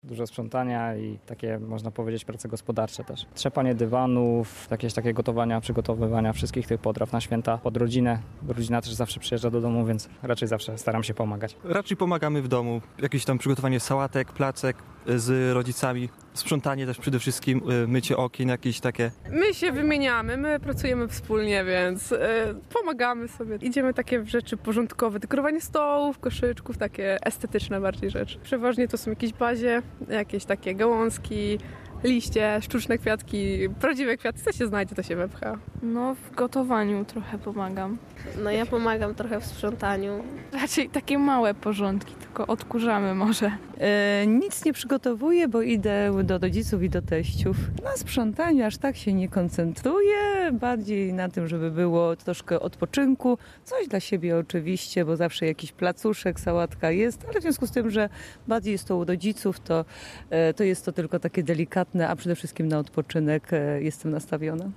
Jak rzeszowianie przygotowują się do świąt? (sonda)
Zapytaliśmy mieszkańców Rzeszowa, jak u nich wyglądają przedświąteczne przygotowania: